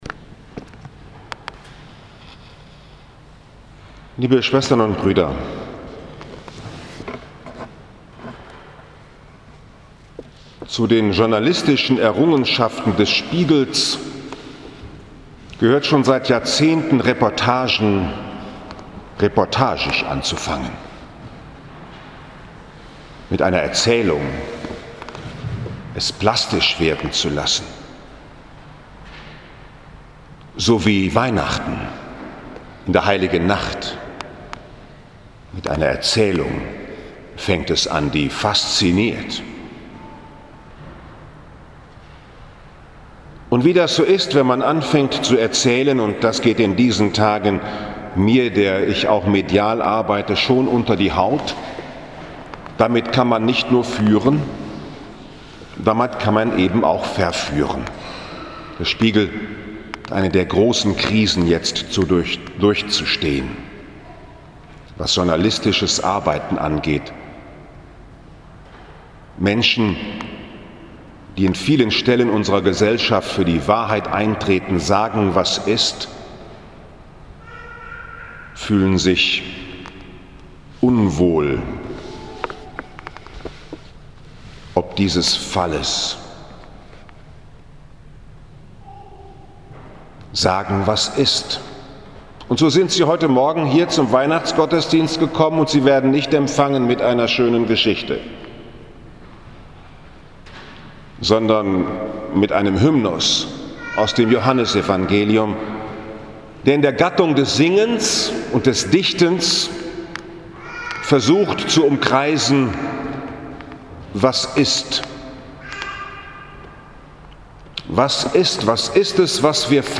Sagen was ist ~ Bruder Paulus´ Kapuzinerpredigt Podcast